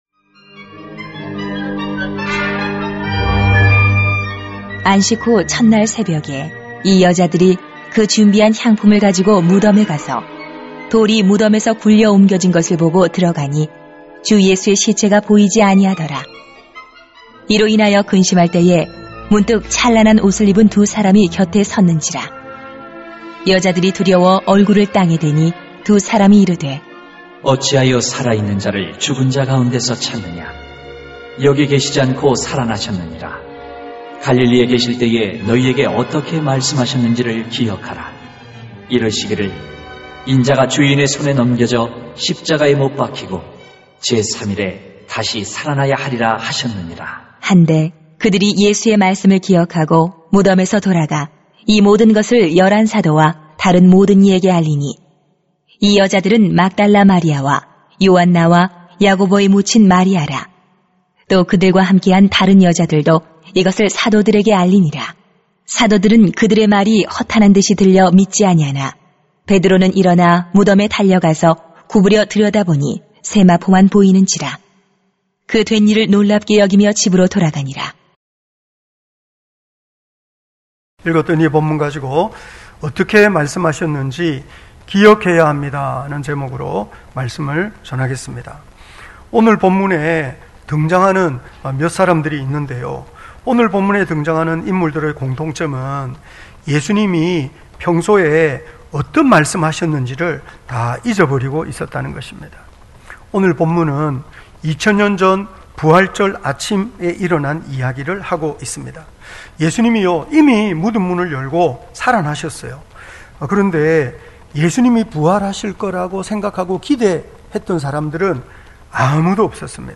2021.4.4 어떻게 말씀하셨는지 기억해야 합니다 > 주일 예배 | 전주제자교회